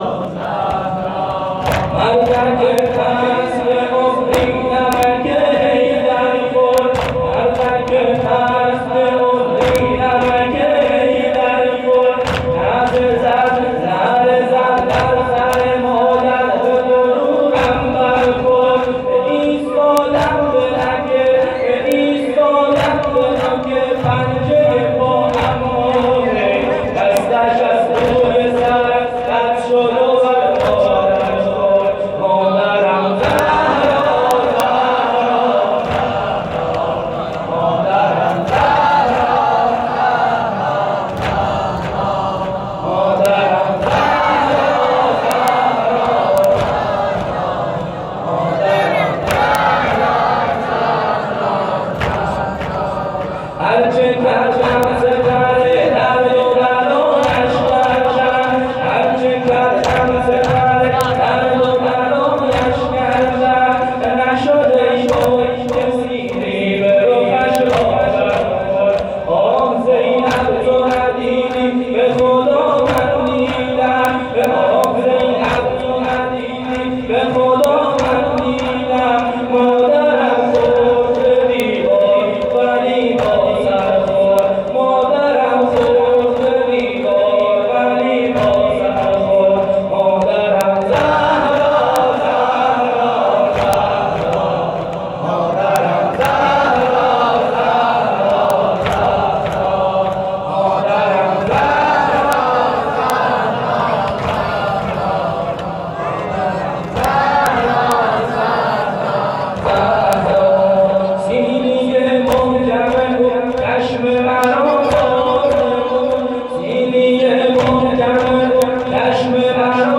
مداحی زابلی